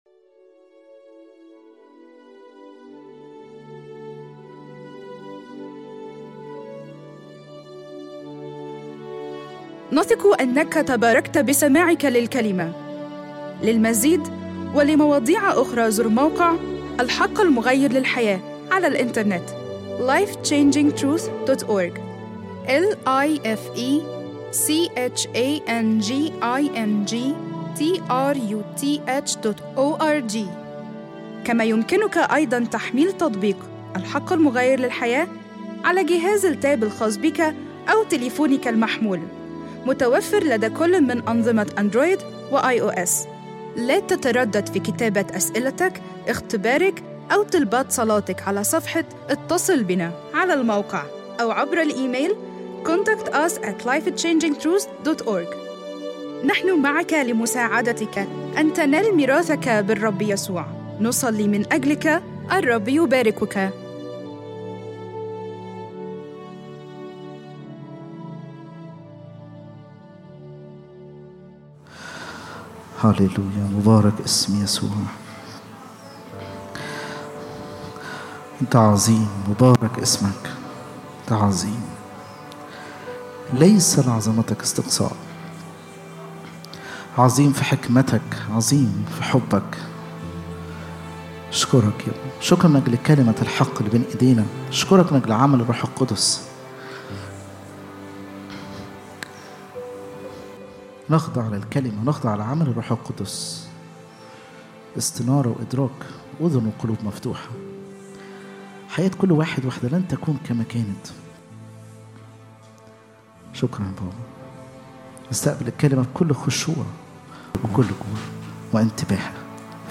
لمشاهدة العظة على الفيس بوك أضغط هنا